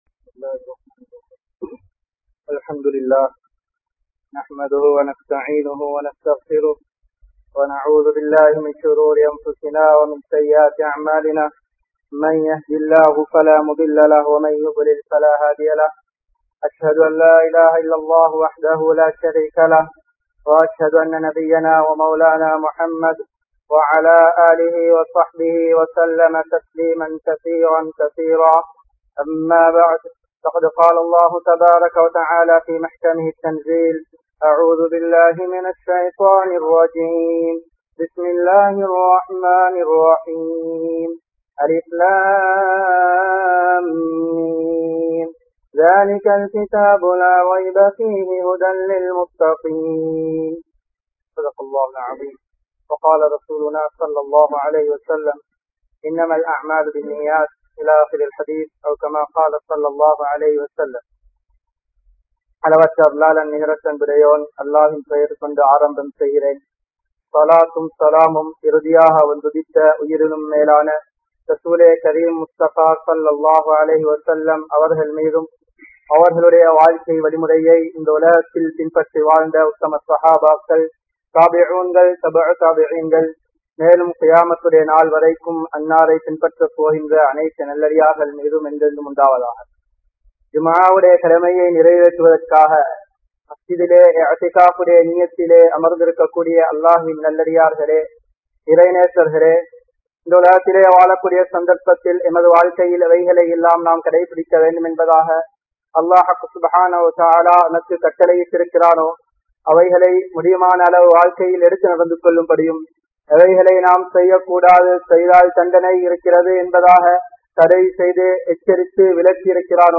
Kadan (கடன்) | Audio Bayans | All Ceylon Muslim Youth Community | Addalaichenai